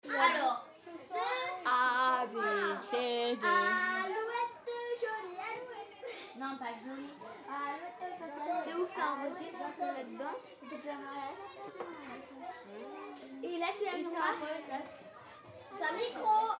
Koenigshoffen - KM140 ORTF / SXR4+